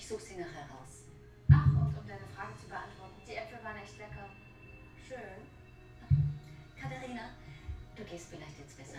Es hat denselben hohen Peak wie in den oberen Beispielen, dazu gesellen sich aber eine Reihe niedrigerer Peaks, die trotzdem deutlich hörbar und unangenehm sind.